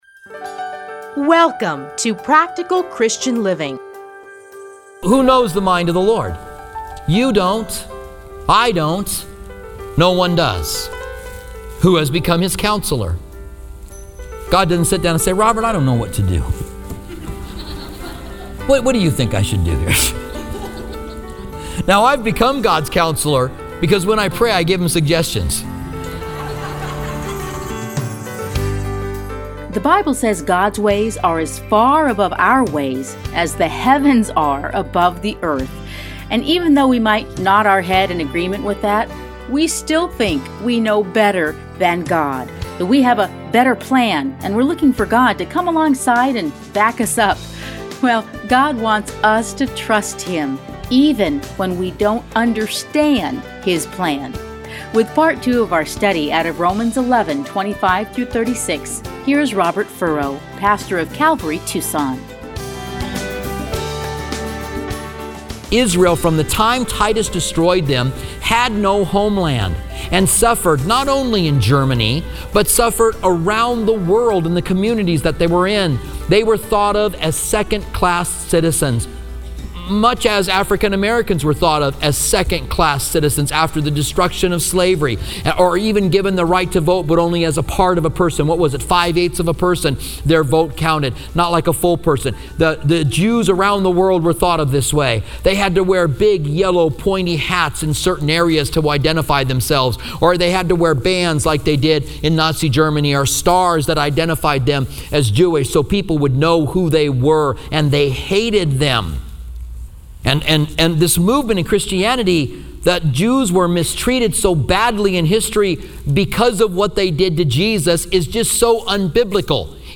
Listen here to his commentary on Romans.